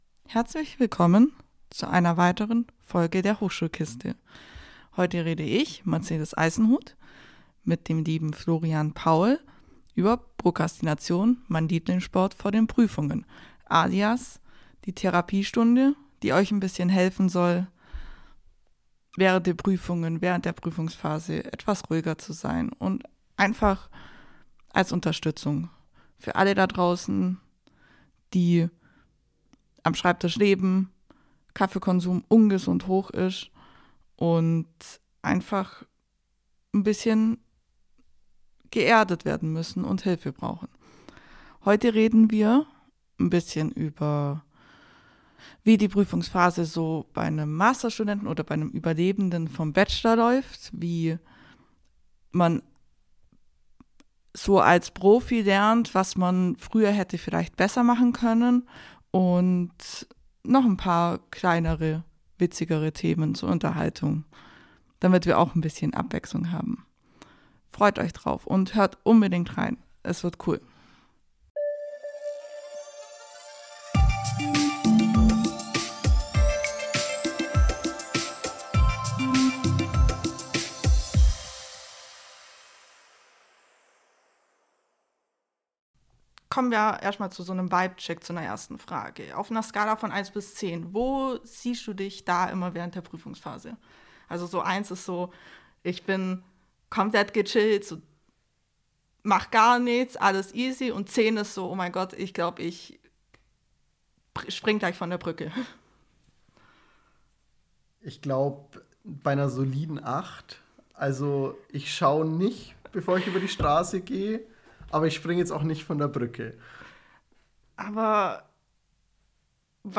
In dieser Folge haben wir zwei echte Veteranen zu Gast.